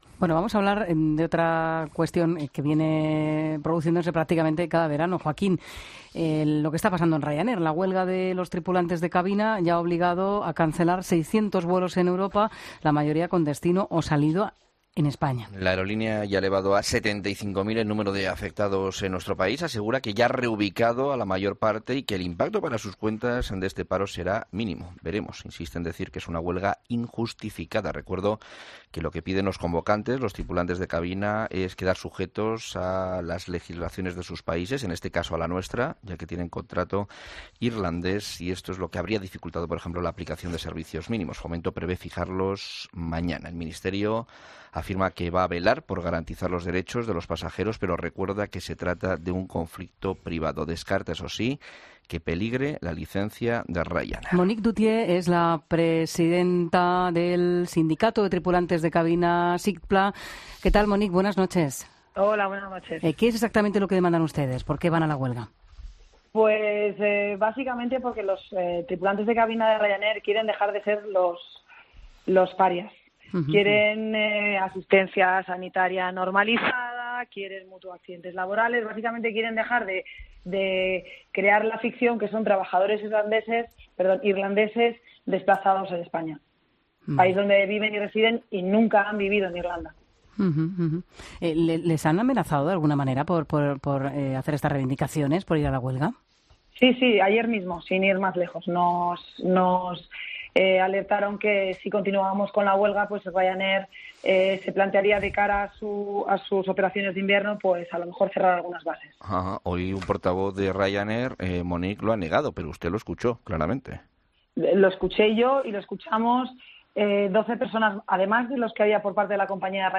Representantes de los dos sindicatos convocantes de la huelga de trabajadores de Ryanair para los días 25 y 26 de julio han hablado con el ‘Equipo Económico’ de ‘La Linterna’ para subrayar sus reivindicaciones y comentar la situación actual de las negociaciones y la actitud del gobierno al respecto.